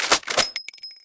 Media:RA_Shelly_Evo.wav UI音效 RA 在角色详情页面点击初级、经典和高手形态选项卡触发的音效